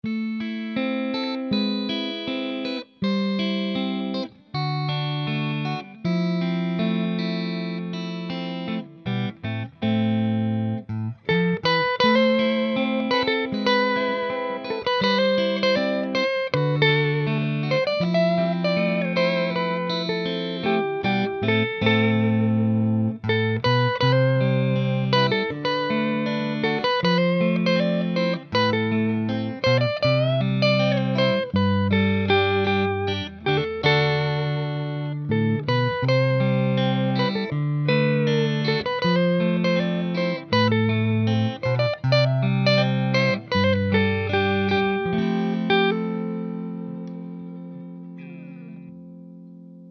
Ñîôòîâàÿ ñïèêåðñèìóëÿöèÿ è ðåâåð (GuitarRig2).
÷èñòûé çâóê. çàïèñàí òåì æå ìåòîäîì, ÷òî è "ãðÿçíûé".